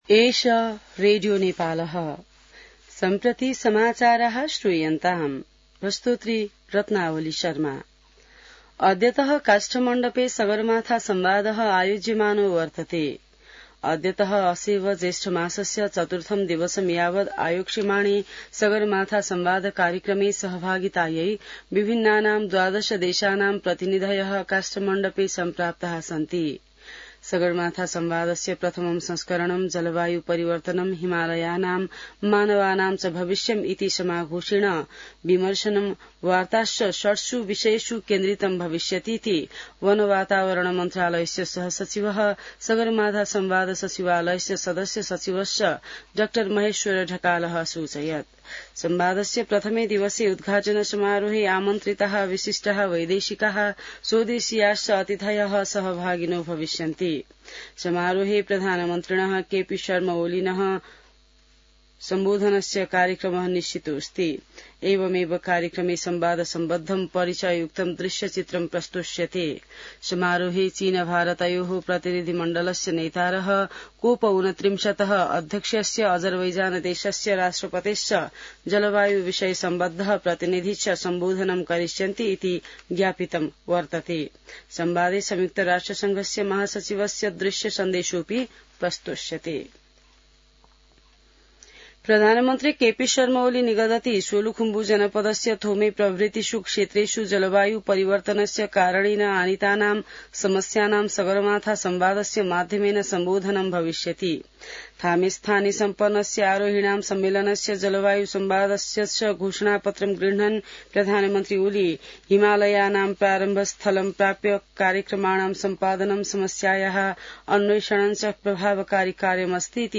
An online outlet of Nepal's national radio broadcaster
संस्कृत समाचार : २ जेठ , २०८२